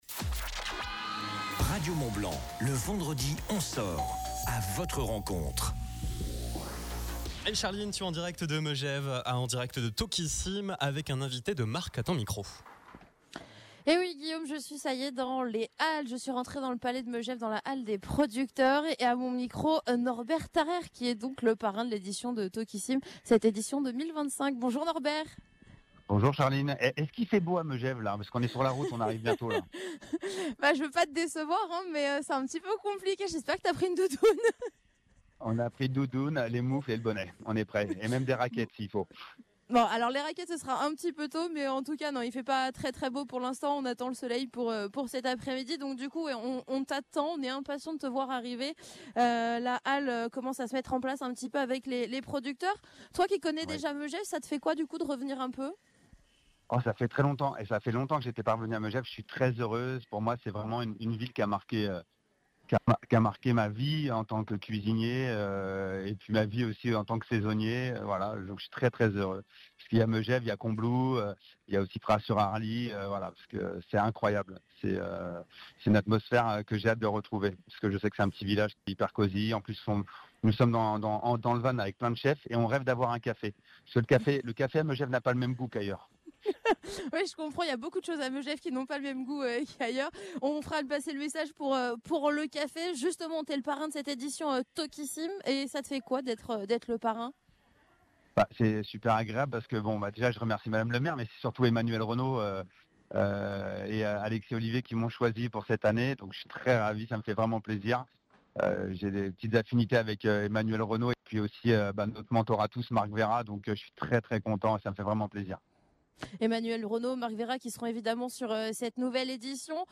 Toquicimes | Interview de Norbert Tarayre
Écoutez son interview exclusive sur Radio Mont Blanc pour en savoir plus sur les temps forts de l’événement !